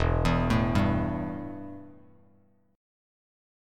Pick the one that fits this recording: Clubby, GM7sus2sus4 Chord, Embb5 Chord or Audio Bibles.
Embb5 Chord